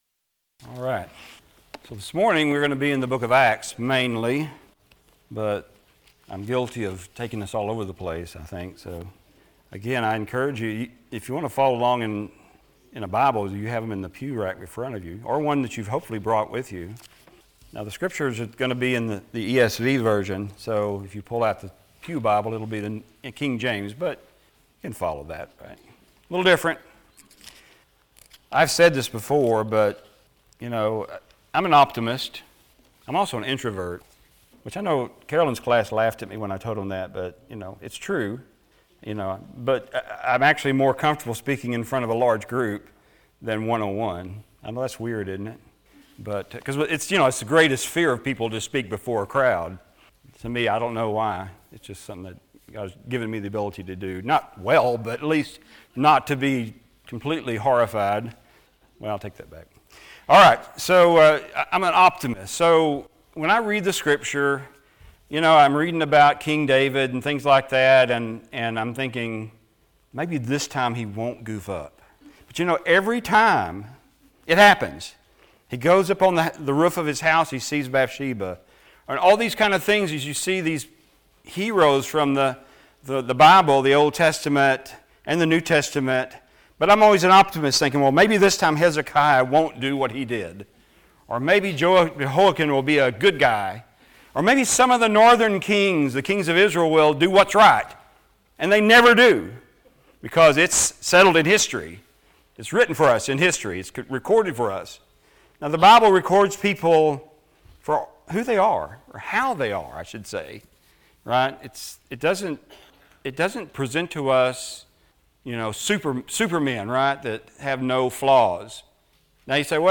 Passage: Acts 11:19-24 Service Type: Sunday Morning If you live in our area